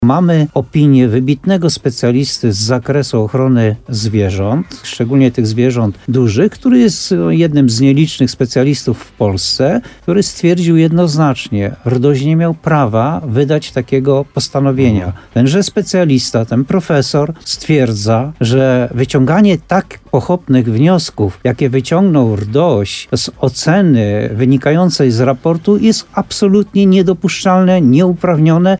– Jesteśmy już w innym miejscu w tej sprawie – poinformował w programie Słowo za Słowo w radiu RDN Nowy Sącz burmistrz Muszyny Jan Golba.